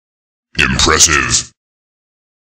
Quake 3 sounds
impressive.ogg